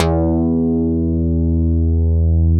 Index of /90_sSampleCDs/Roland LCDP02 Guitar and Bass/BS _Synth Bass 1/BS _MIDI Bass